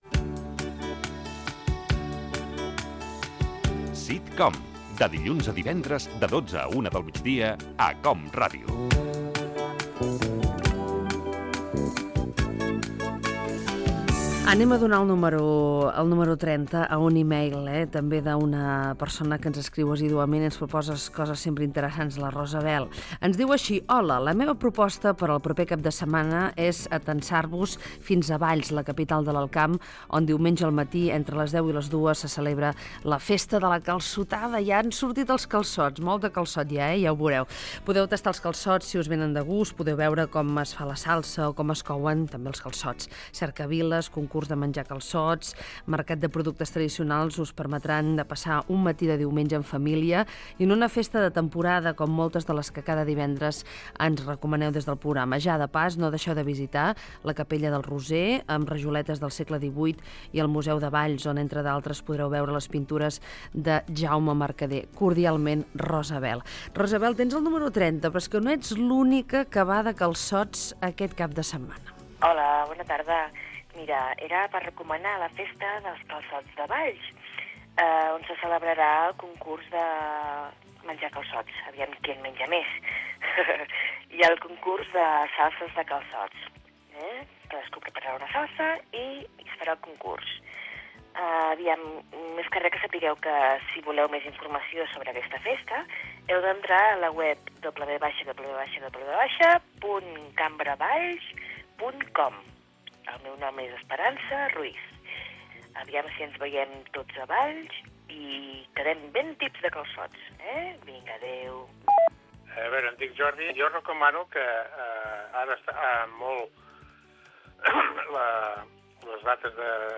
Indicatiu del programa i participació dels oients amb propostes per al cap de setmana
Entreteniment